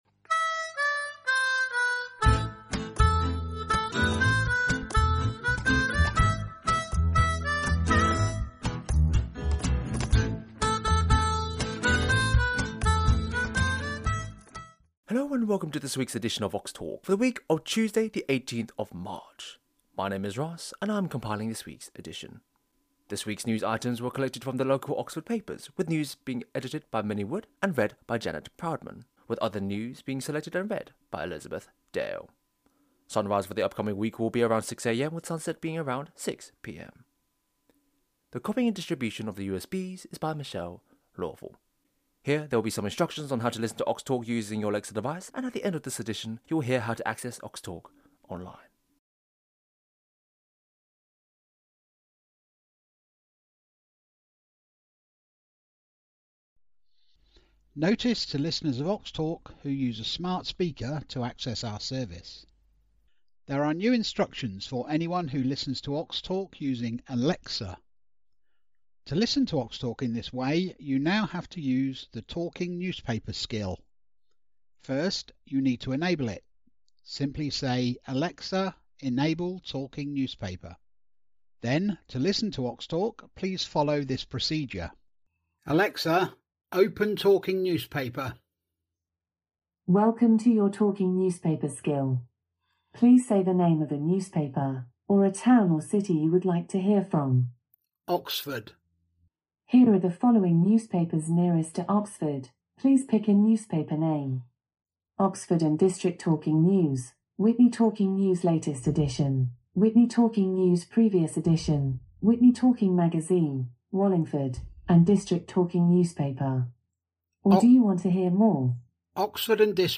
18th March 2025 edition - Oxtalk - Talking newspapers for blind and visually impaired people in Oxford & district